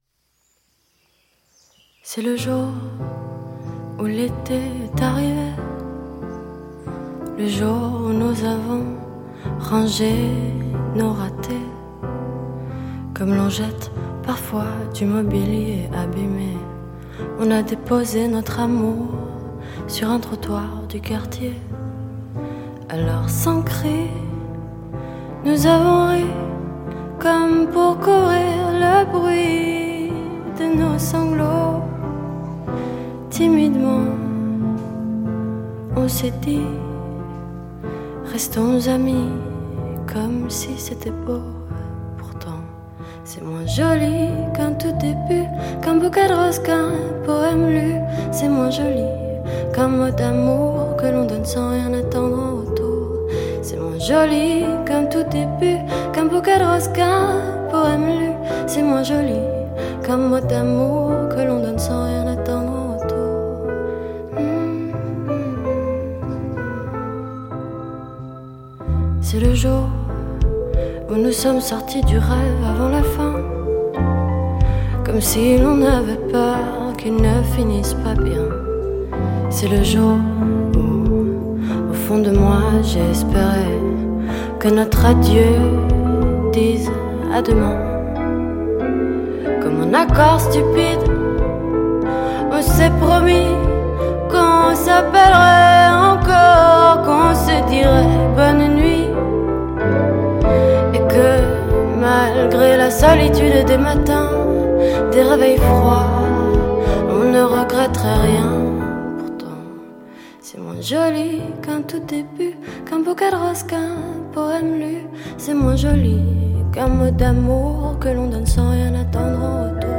آهنگ فرانسوی